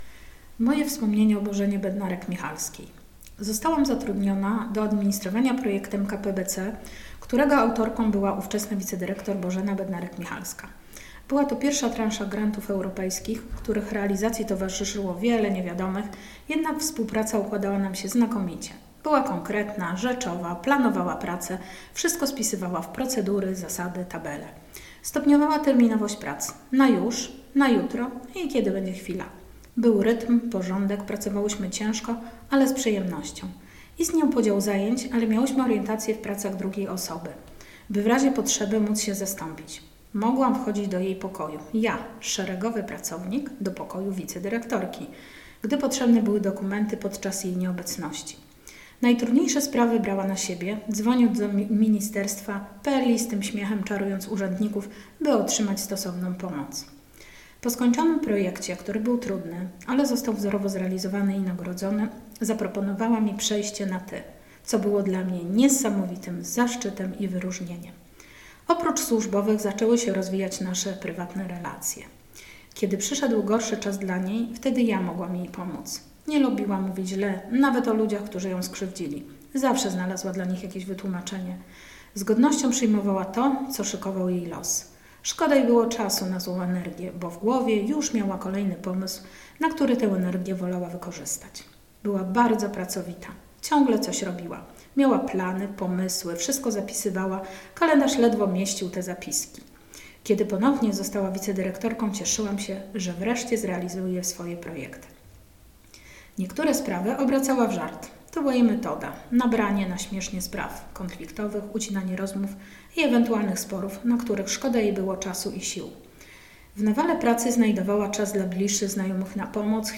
Historia mówiona